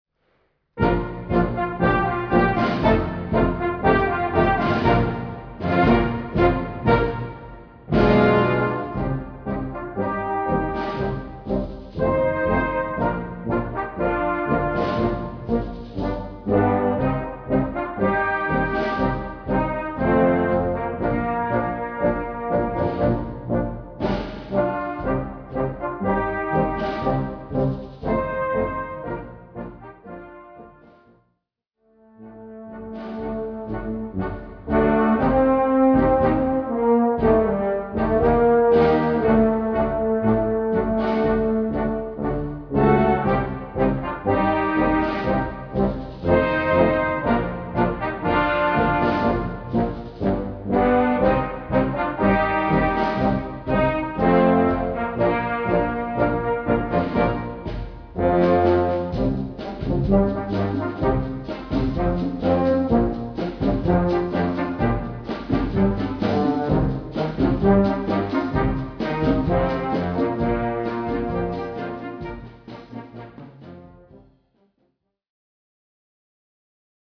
Tango & Cha Cha